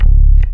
BASS 4 110-R.wav